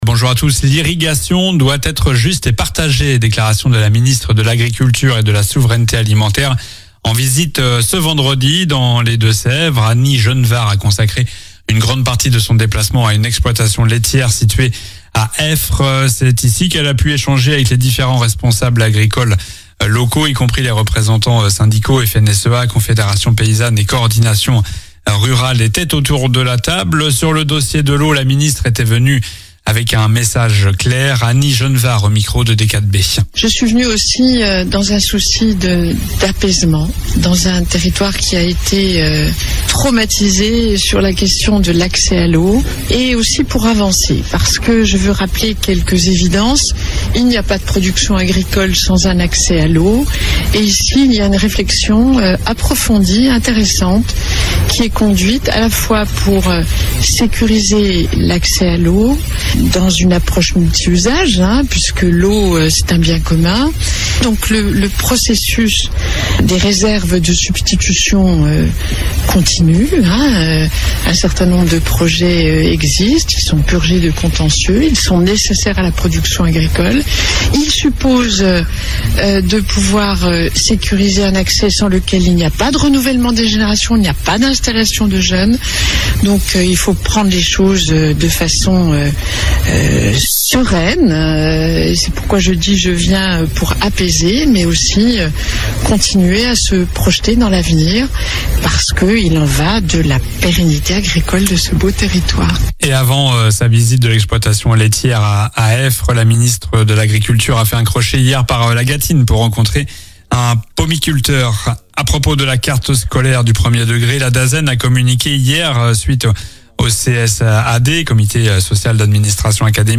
COLLINES LA RADIO : Réécoutez les flash infos et les différentes chroniques de votre radio⬦
L'info près de chez vous